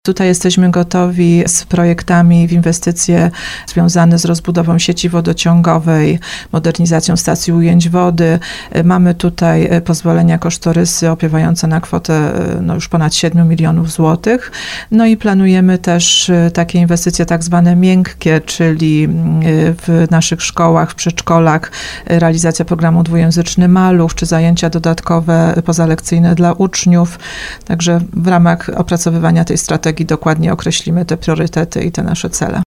– mówiła wójt Małgorzata Kras